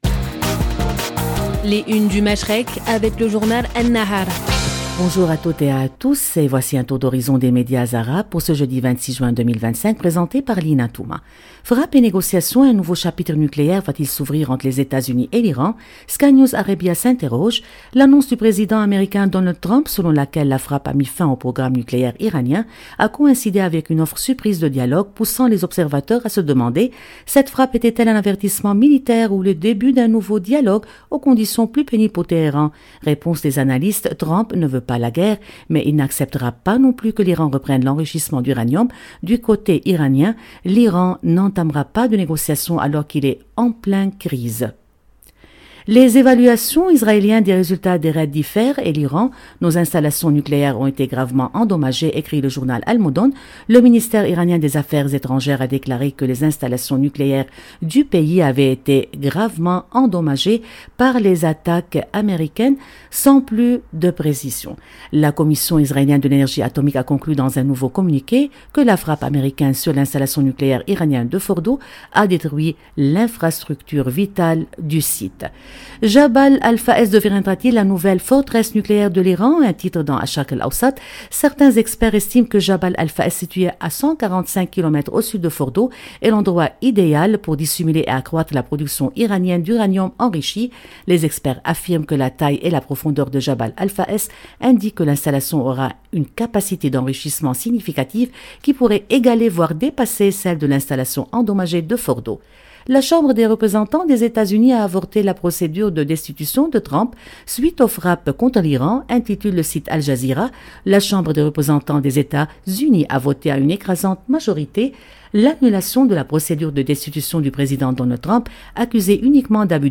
Chaque matin, Radio Orient vous propose, en partenariat avec le journal libanais An-Nahar, une revue de presse complète des grands titres du Moyen-Orient et du Golfe.